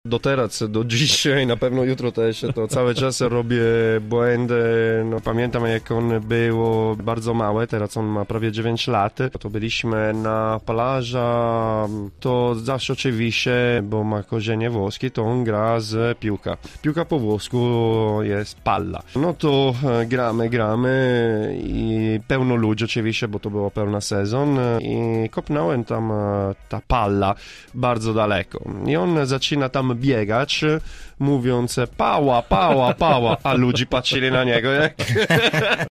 A my dzisiaj w studiu Radia Zielona Góra braliśmy korepetycje z języka włoskiego.